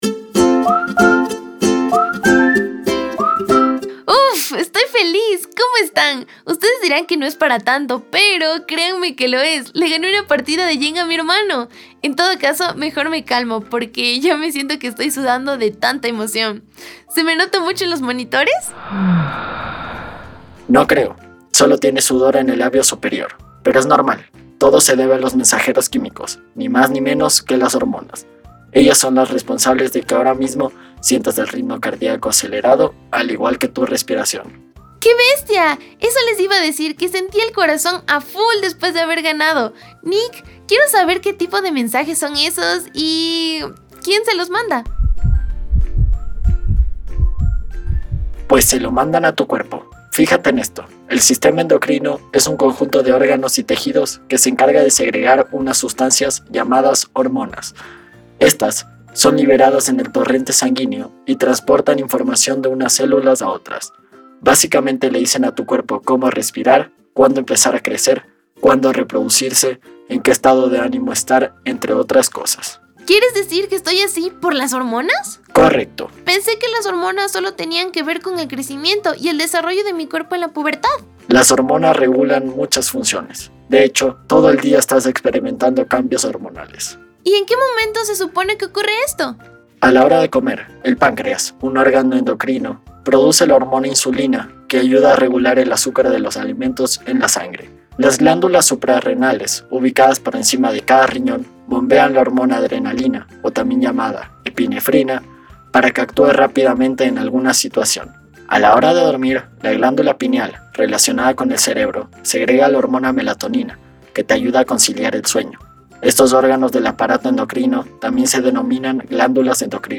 Descripción:  En este episodio, Martina está muy concentrada en un partido de jenga, al iniciar el stream, se apresura y gana, celebra su victoria de forma efusiva y Nicasio reproduce una canción para acompañar su triunfo. A partir de esta situación, Nicasio guía a Martina y a sus seguidores por el fabuloso mundo de las hormonas, donde conocemos cual es el órgano encargado de producirlas, su función, importancia y cuidados.